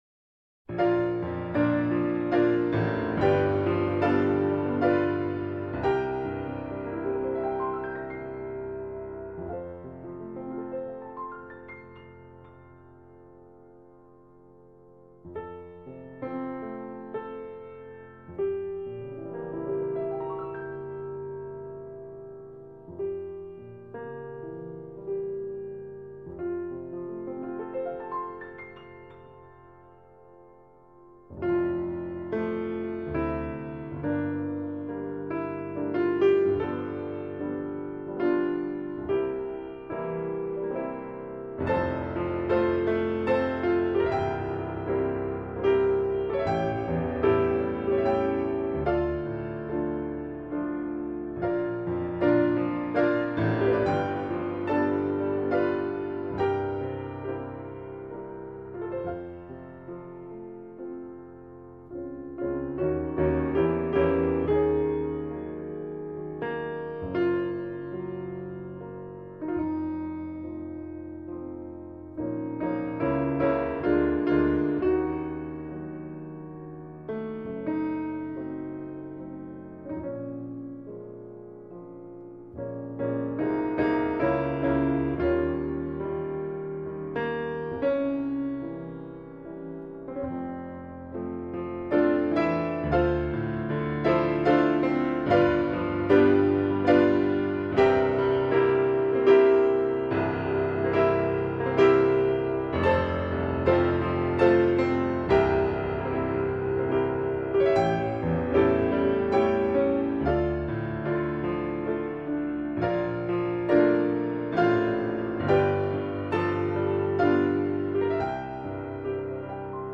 เปียโน